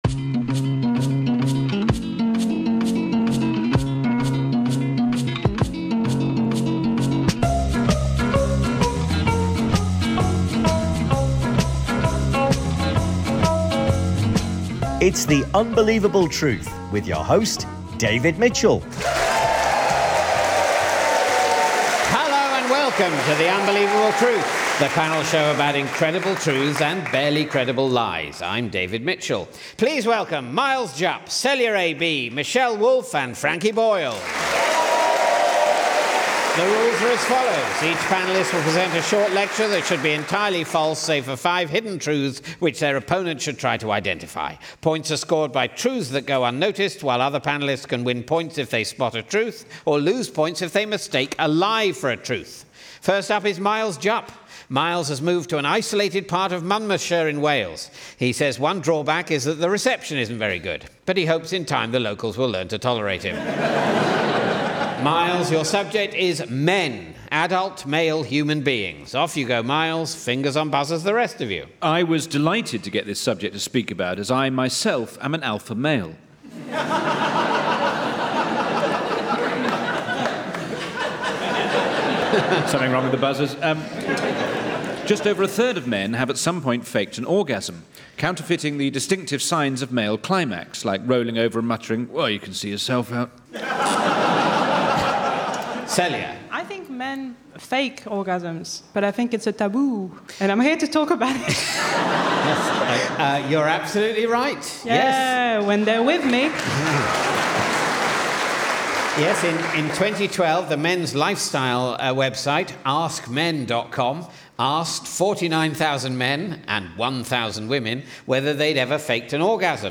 David Mitchell hosts the panel game in which four comedians are encouraged to tell lies and compete against one another to see how many items of truth they're able to smuggle past their…